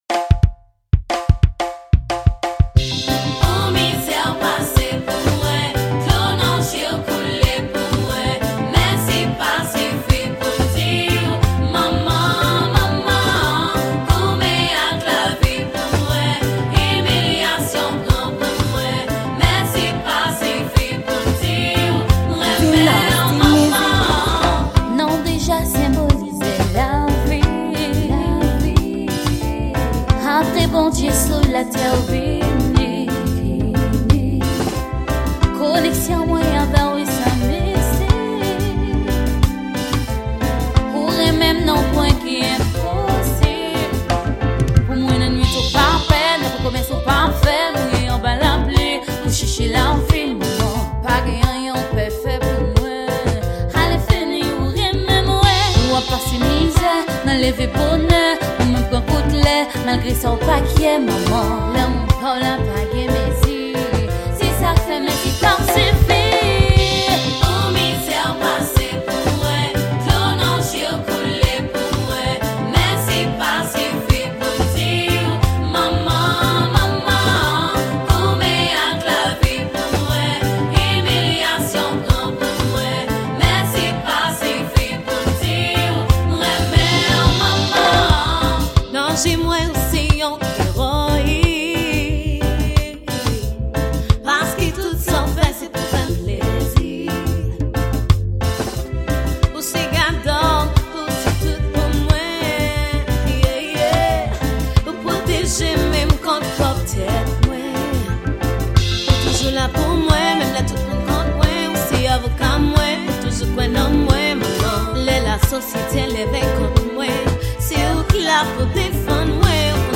Genre: Reggae.